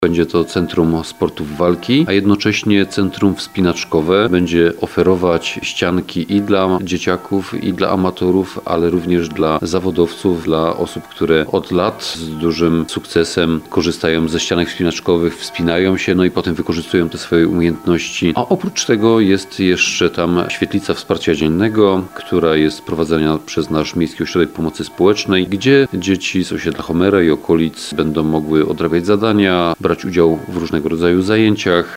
- Będzie to centrum wspinaczkowe i centrum sportów walki. Oprócz tego, w tutejszej świetlicy wsparcia dziennego, dzieci będą mogły odrabiać zadania i brać udział w różnych zajęciach - mówi burmistrz miasta, Krystian Grzesica.